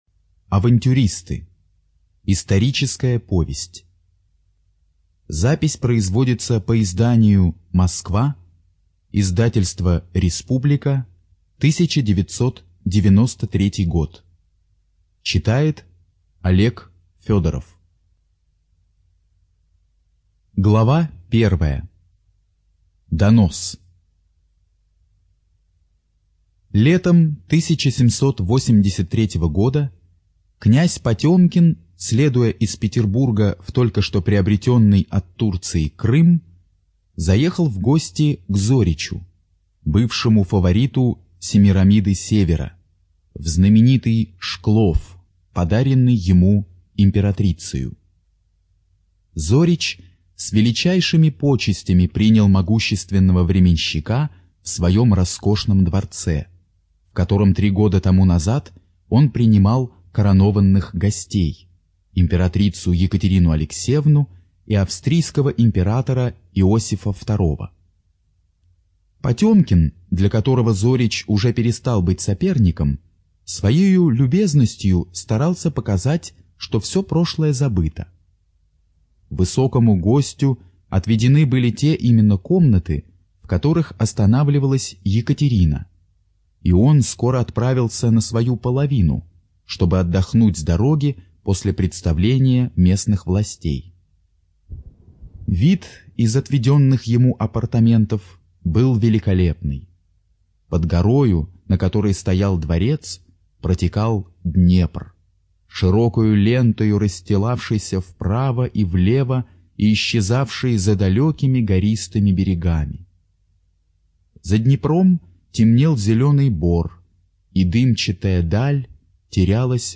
Студия звукозаписиВира-М